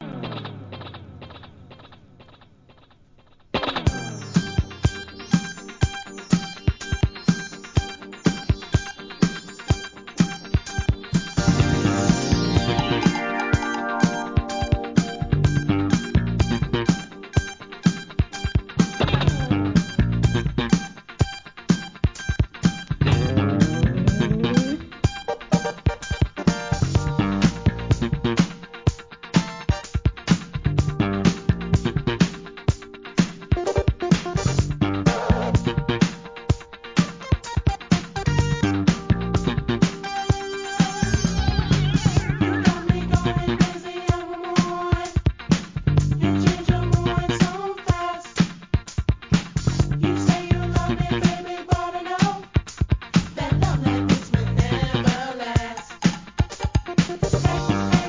1. SOUL/FUNK/etc...
印象的なシンセの1983年 FUNKナンバー!!